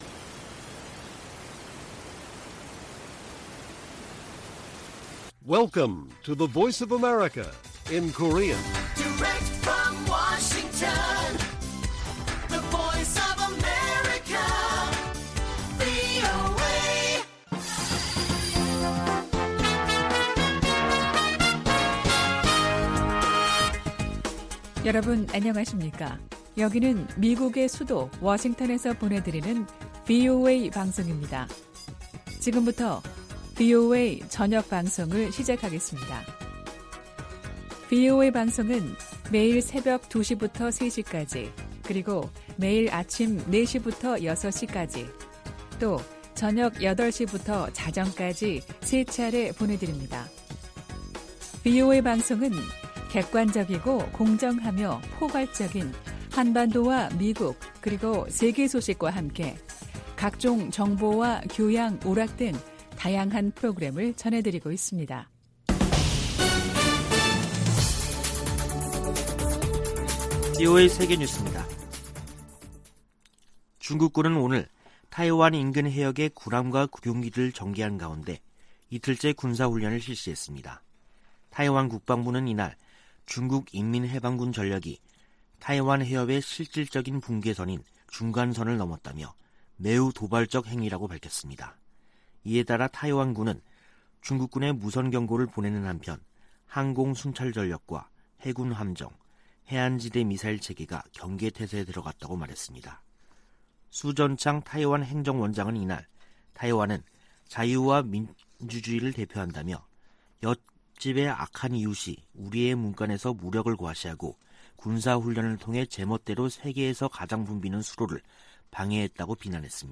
VOA 한국어 간판 뉴스 프로그램 '뉴스 투데이', 2022년 8월 5일 1부 방송입니다. 토니 블링컨 미 국무장관은 타이완 해협에서 무력으로 변화를 노리는 어떠한 시도에도 반대한다고 말했습니다. 타이완 정부는 미 하원의장의 방문을 중국에 대한 내정간섭이라고 북한이 주장한 데 대해, 타이완 주권 폄훼라고 비난했습니다. 필립 골드버그 신임 주한 미국대사는 한반도 비핵화가 미국의 목표라며 북한과 조건없는 대화에 열려 있다고 밝혔습니다.